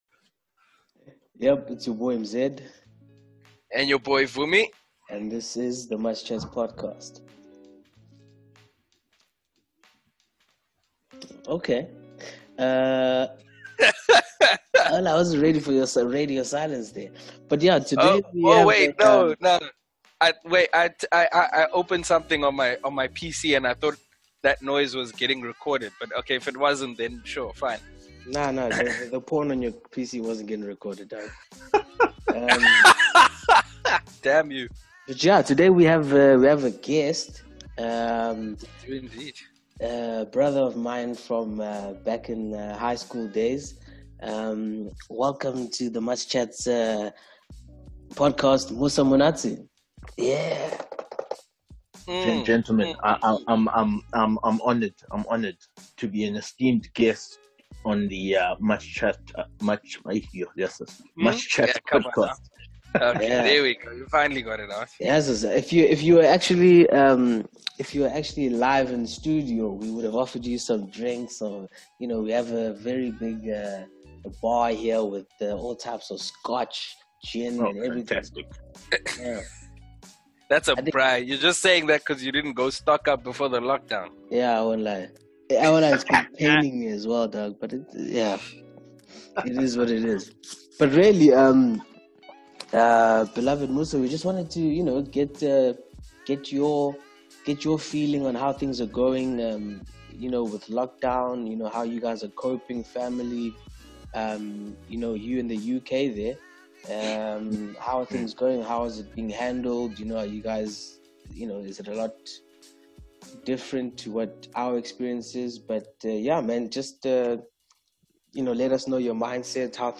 The boys are back with a special guest!